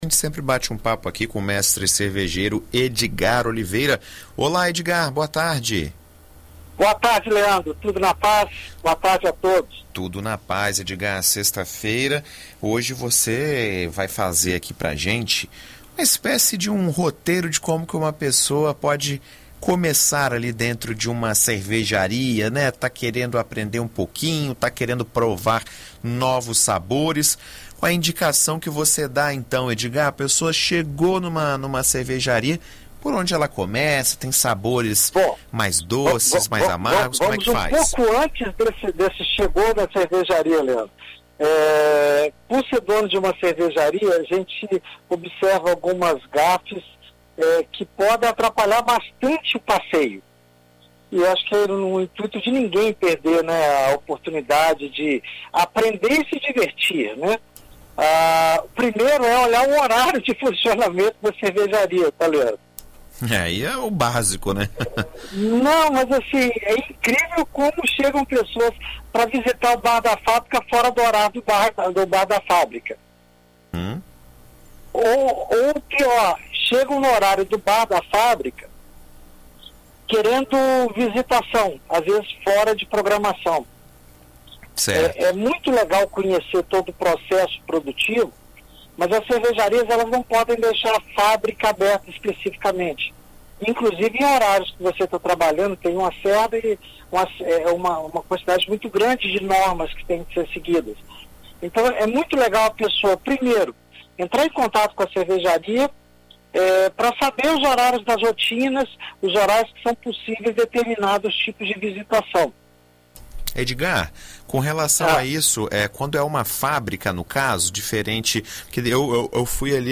Na BandNews FM, mestre cervejeiro faz um guia de quais cervejas tomar em cervejarias artesanais e dá dicas de degustação